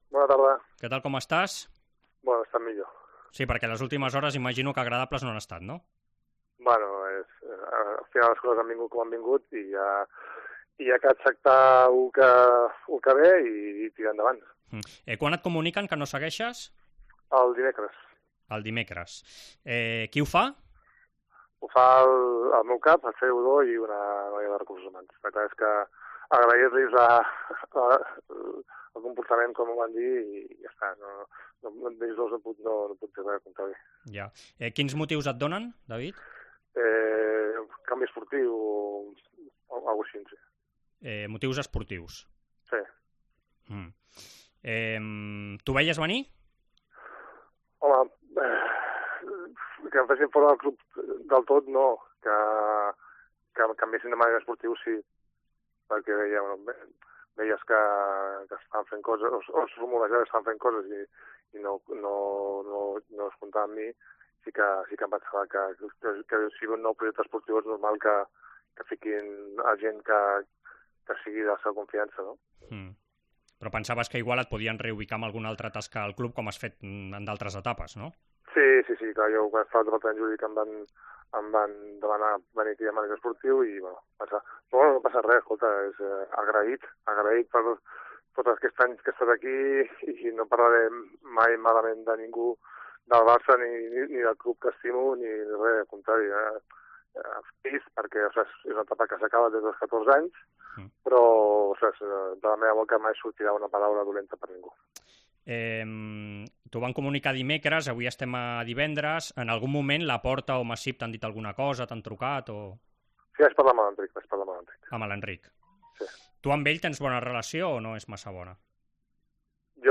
El mítico exportero del Barça ha atendido a los micrófonos de Esports COPE pocas horas después de comunicar al equipo que el club ha decidido prescindir de sus servicios como mánager deportivo de la sección de balonmano después de 37 años en la entidad.